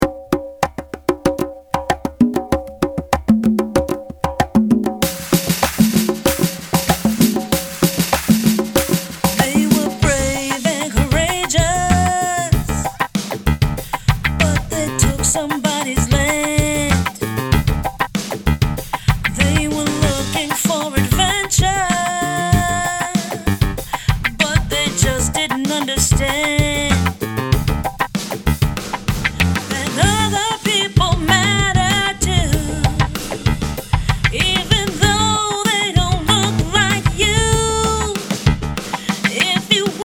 fun and soulful